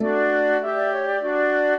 flute-harp